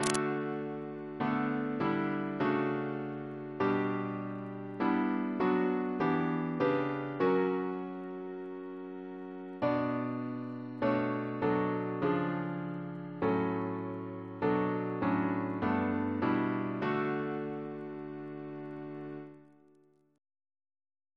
Double chant in D Composer